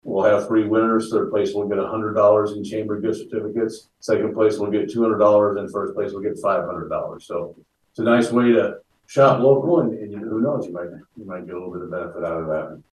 appearing at the Harlan City Council meeting